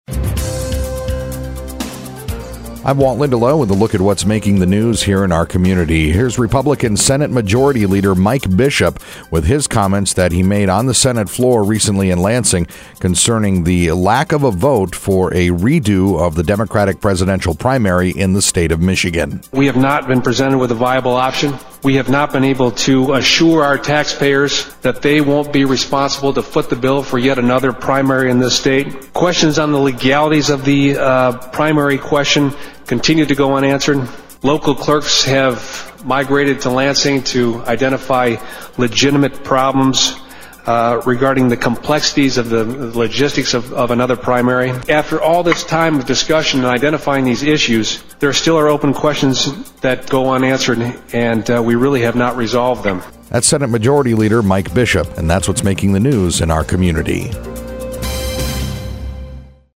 Gallery ThumbnailComments from the Senate Majority Leader about why there were not enough votes in the Republican controlled Michigan Senate to support a re-do of the Democratic Presidential Primary in Michigan.